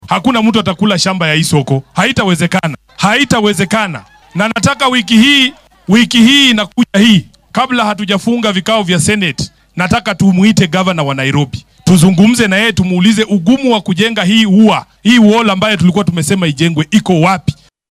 Dhankiisa, senatarka ismaamulka Nairobi ahna xoghayaha guud ee xisbiga ODM Edwin Sifuna ayaa sheegay in aynan jirin cid si sharci darro ah ku qaadan karto dhulka uu suuqa Toi ku yaalla.